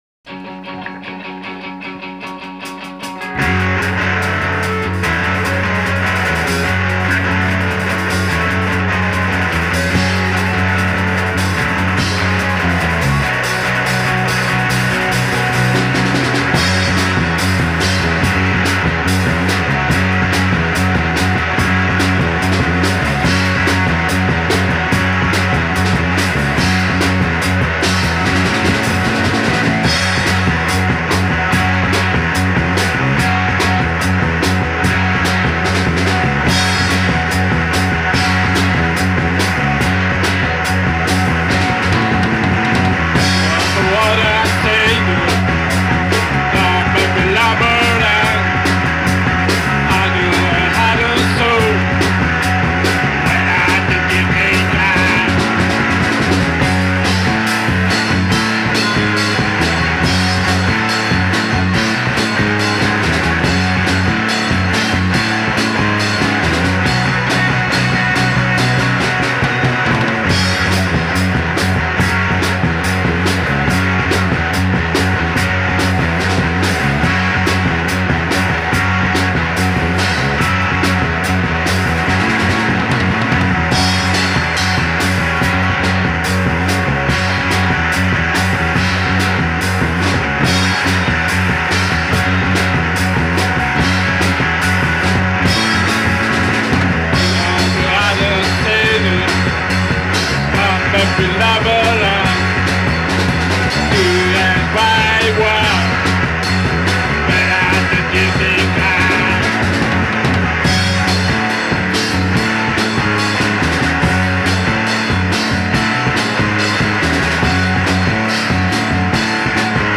Ahora, a esto le llaman "Lo-fi".